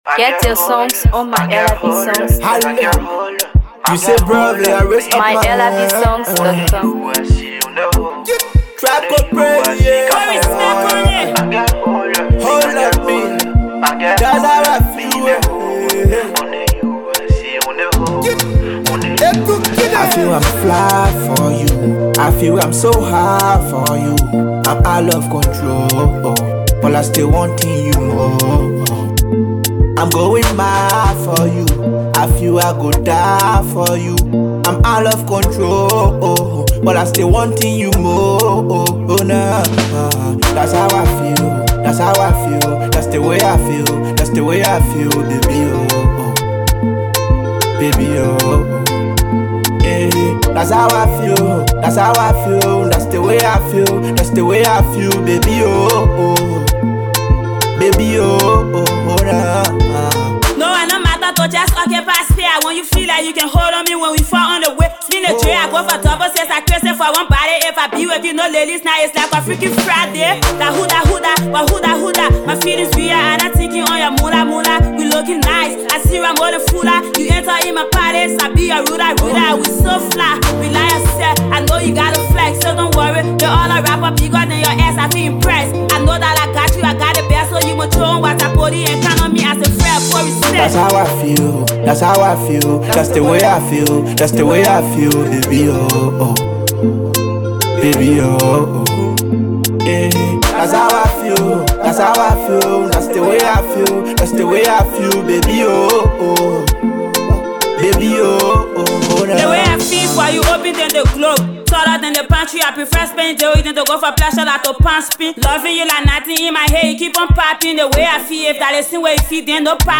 Afro Pop